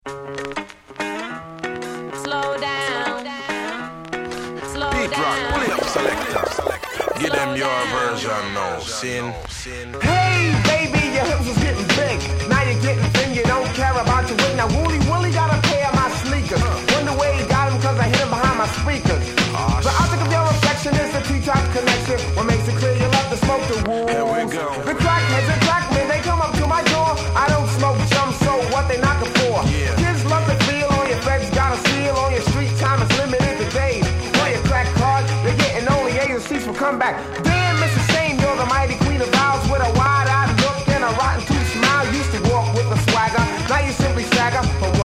※試聴ファイルは別の盤から録音してございます。